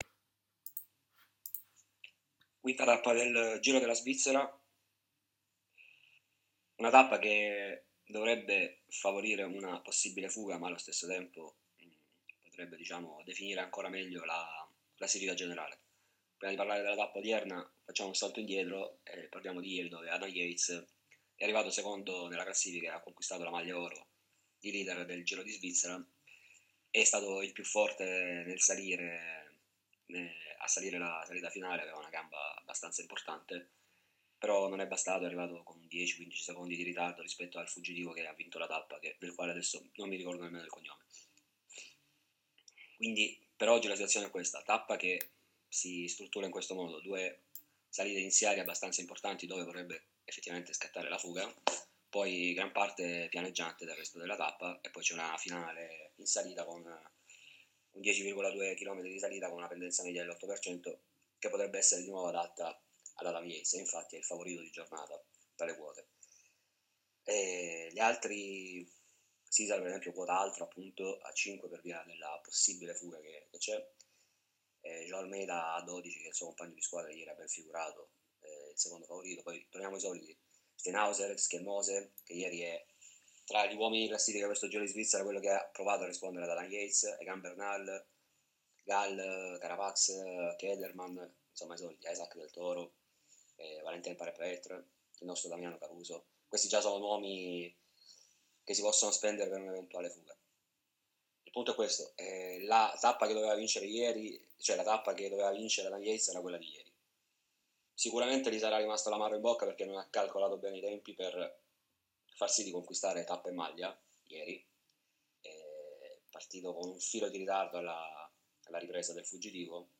In questo audio le analisi e i consigli per le scommesse del nostro esperto sui Pronostici Ciclismo: